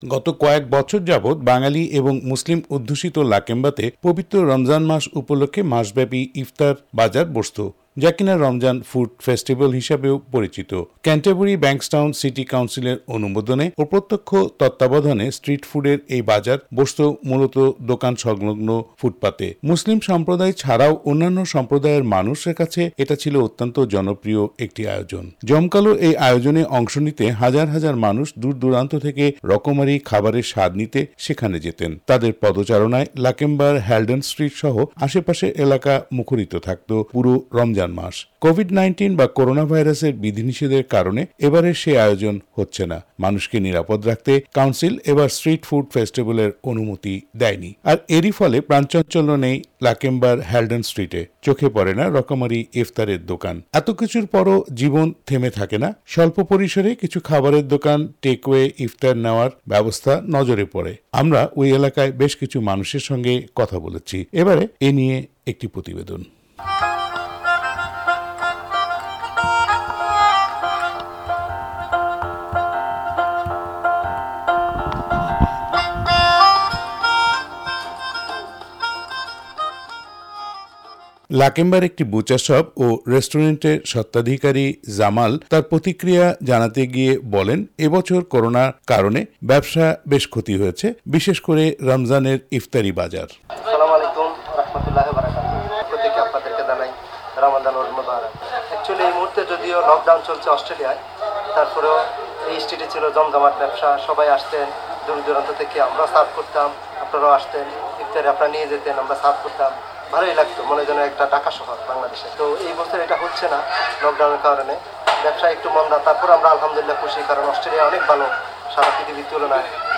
একটি প্রতিবেদন।